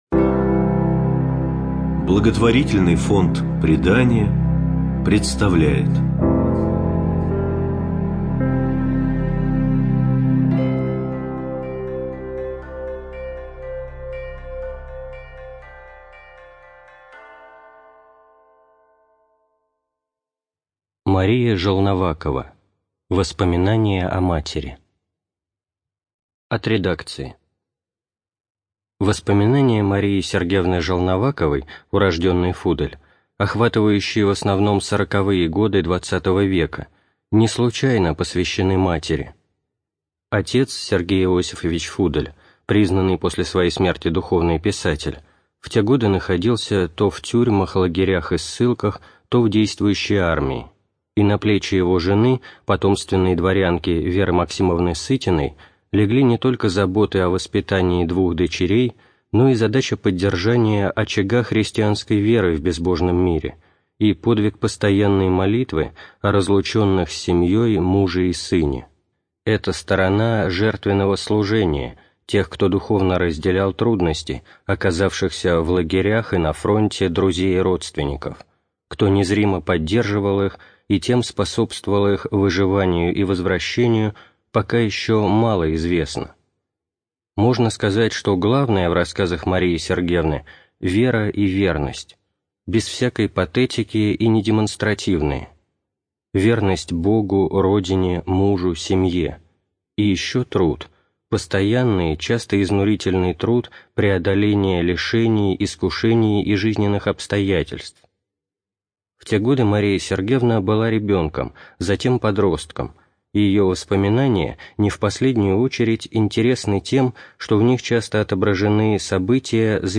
Студия звукозаписиПредание